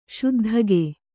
♪ śuddhage